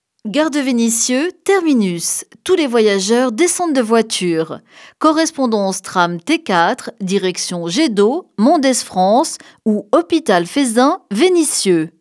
Métro D - Annonce station